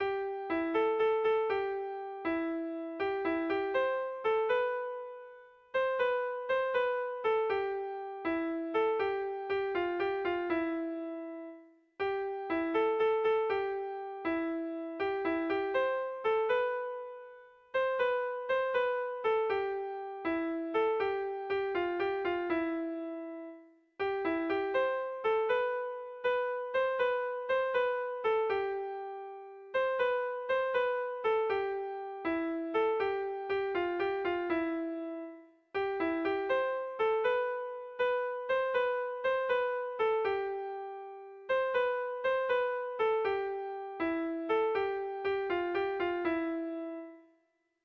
Bertso melodies - View details   To know more about this section
Sentimenduzkoa
Zortziko txikia (hg) / Lau puntuko txikia (ip)